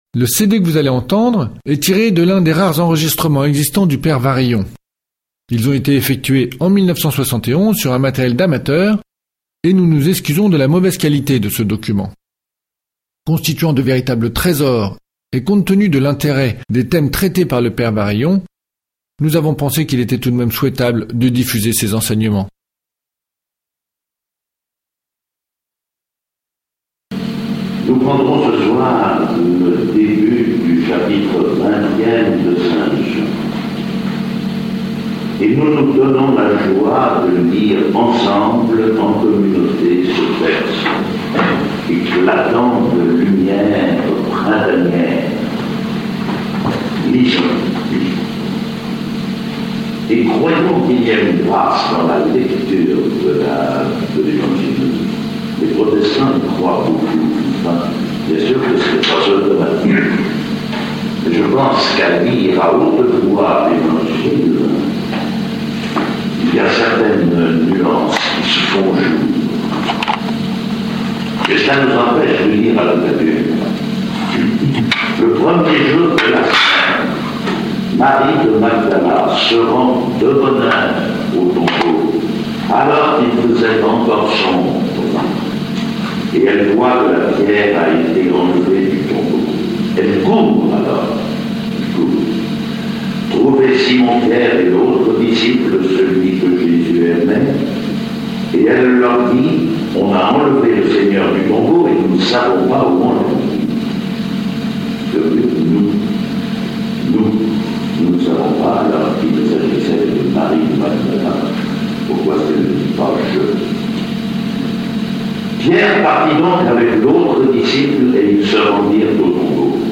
Enseignement
Document d'archive, enregistré en 1971
Intervenant(s) : P.François Varillon, s.j.
Format :MP3 64Kbps Mono